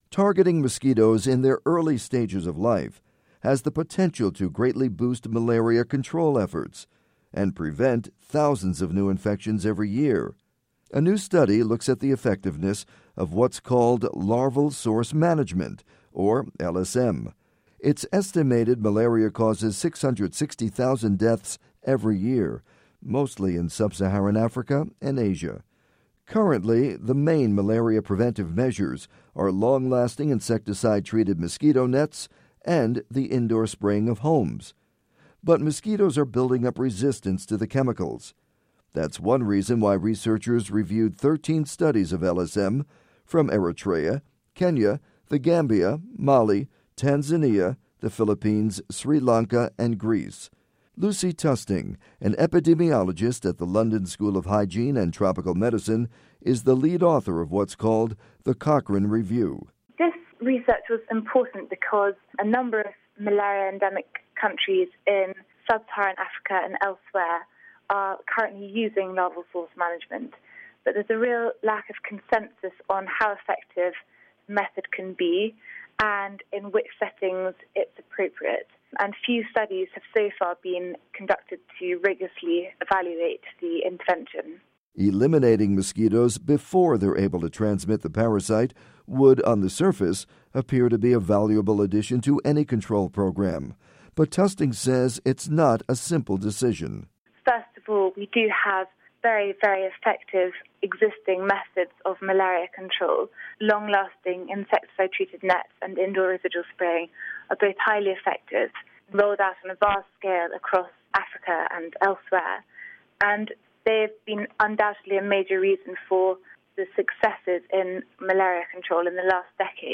report on malaria mosquito control